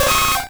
Cri de Nidoran♂ dans Pokémon Rouge et Bleu.